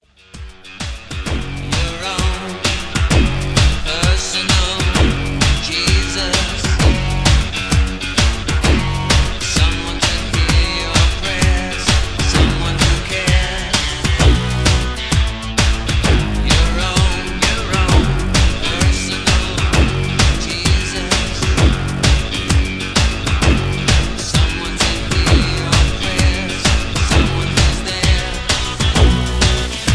Karaoke MP3 Backing Tracks
Just Plain & Simply "GREAT MUSIC" (No Lyrics).
karaoke , mp3 backing tracks